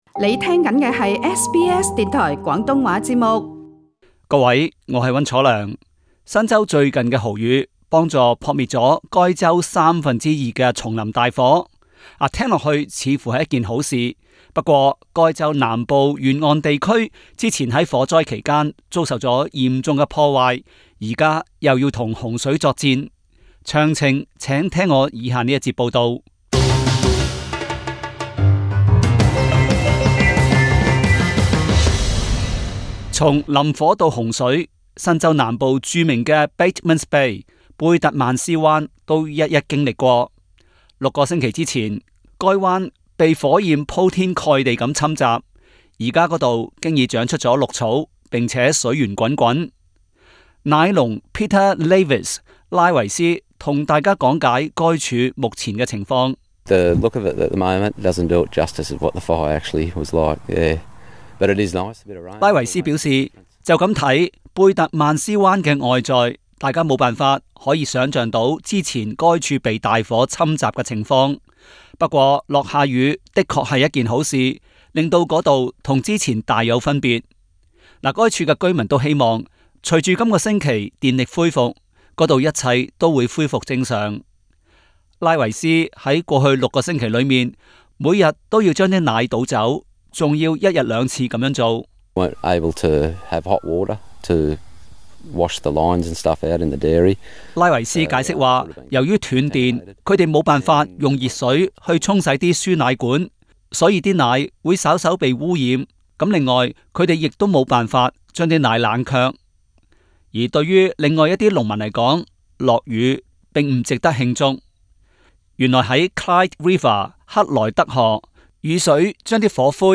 Source: AAP SBS广东话播客 View Podcast Series Follow and Subscribe Apple Podcasts YouTube Spotify Download (10.54MB) Download the SBS Audio app Available on iOS and Android 受低压槽影响，新州连日豪雨，帮助扑灭了该州三分之二的丛林大火。